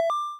end_turn.wav